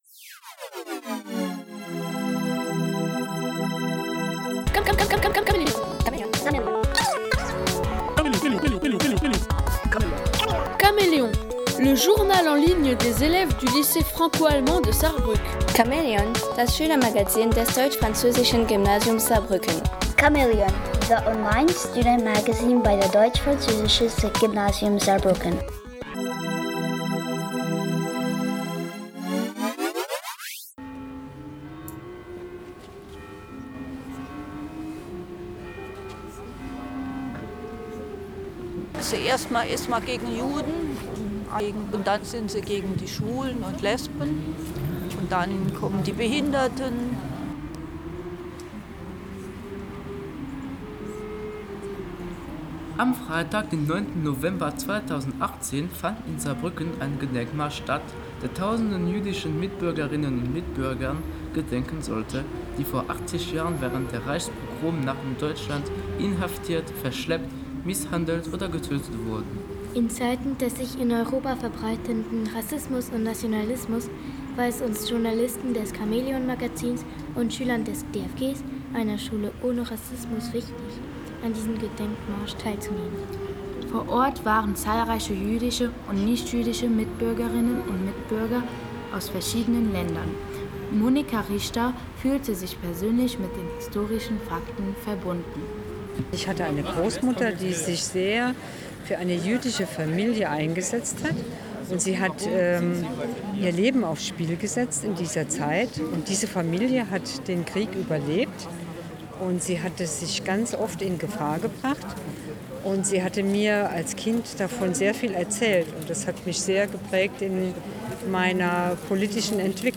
Am 9. November 2018 fand in Saarbrücken ein Gedenkmarsch statt, der tausenden jüdischen Mitbürgerinnen und Mitbürgern gedenken sollte, die vor 80 Jahren während der Reichspogromnacht in Deutschland inhaftiert, verschleppt, misshandelt oder getötet wurden.
Gedenkmarsch-Reichspogromnacht.mp3